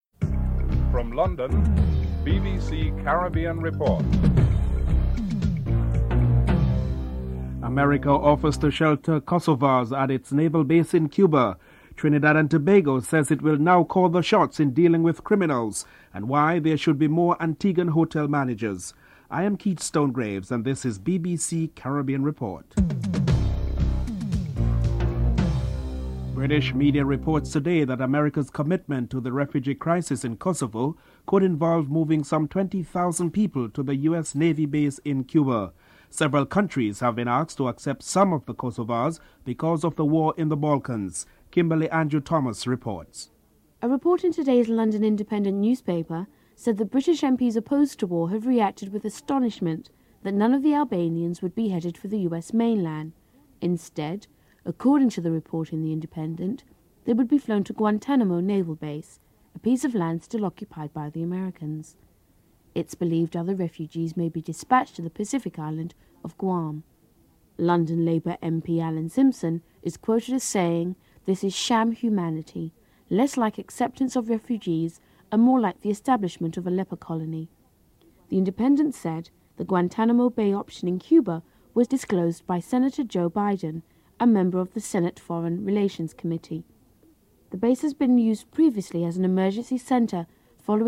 3. Trinidad and Tobago Attorney General Ramesh Lawrence Maharaj discusses the legal rulings by the Privy Council preventing the country from implementing the death penalty.